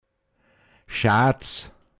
Pinzgauer Mundart Lexikon